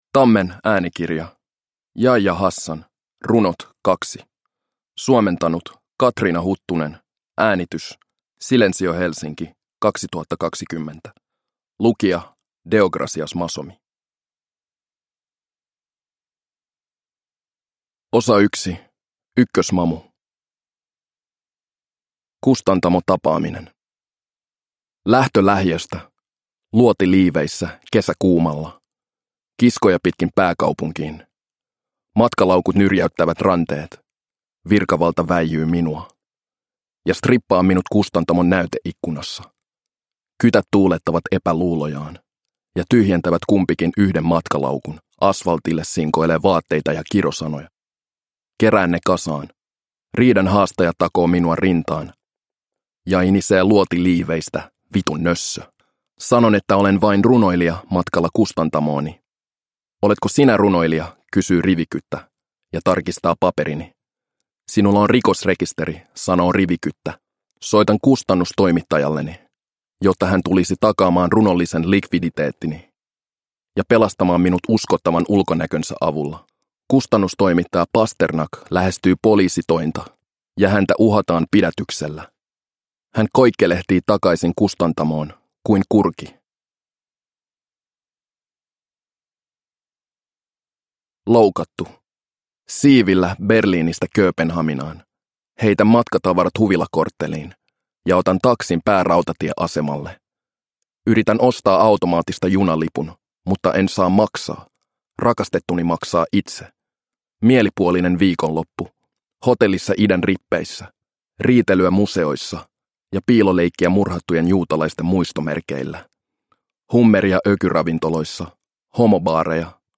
Runot 2 – Ljudbok – Laddas ner